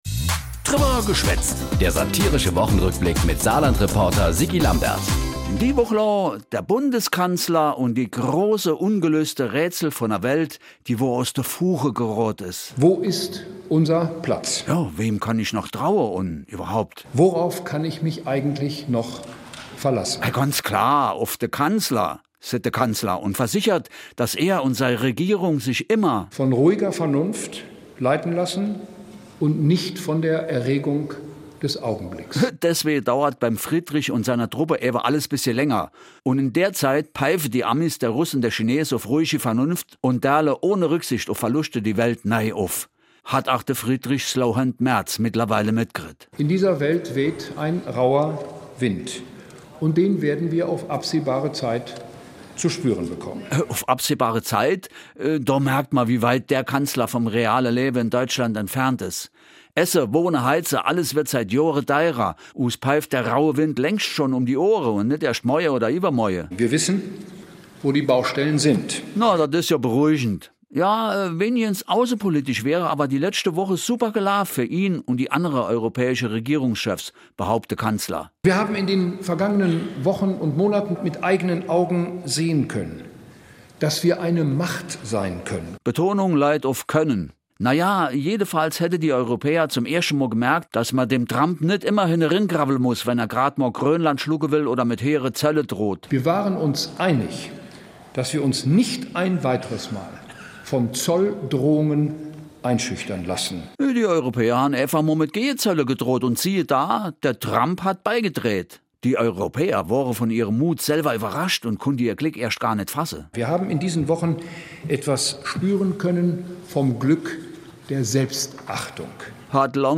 Satirischer Rückblick auf die Ereignisse der Woche jeweils samstags (in Dialekt)